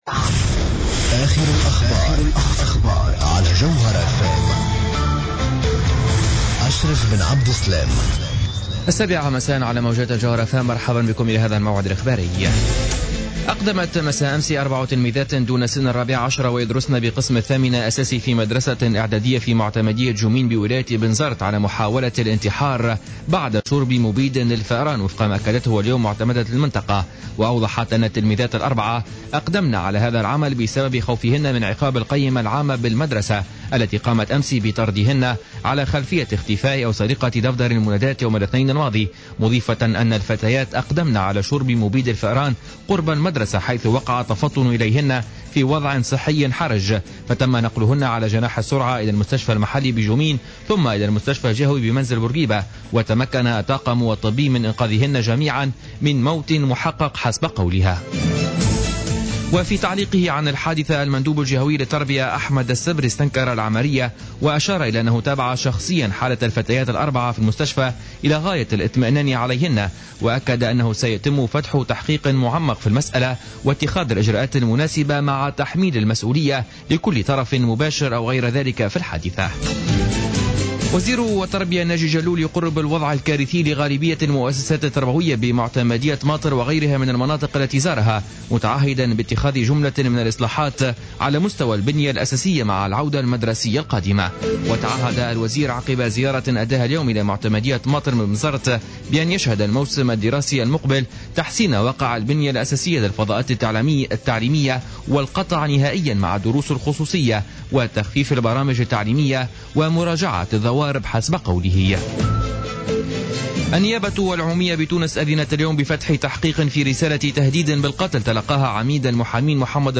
نشرة أخبار السابعة مساء ليوم الاربعاء 1 أفريل 2015